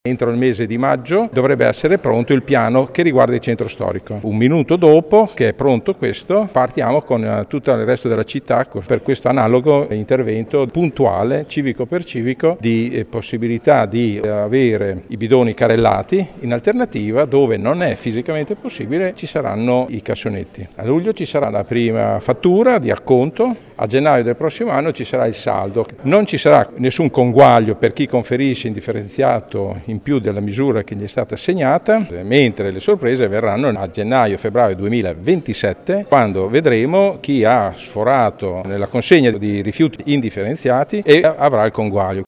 L’assessore all’ambiente Vittorio Molinari: